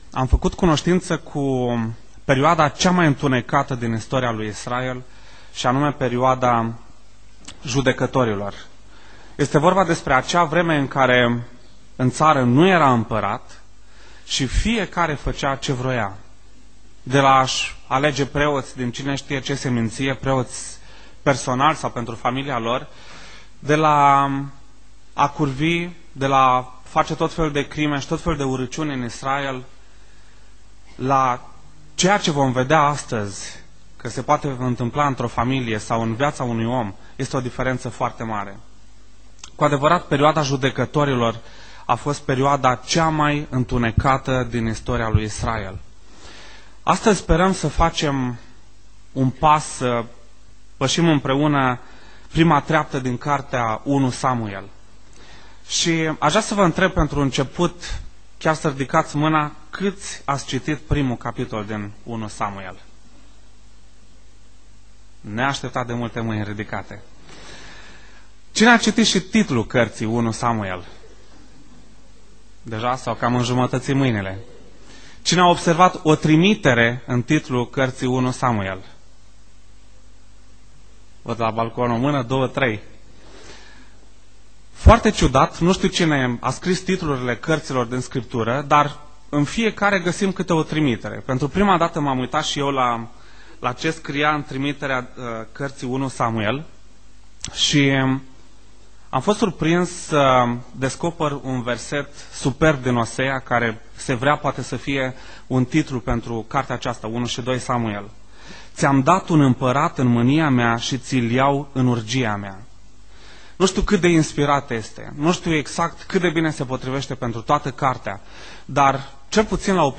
Predica 1 Samuel cap 1 cap 2, Predica audio 1 Samuel cap 1 cap 2, predici audio vechiul testament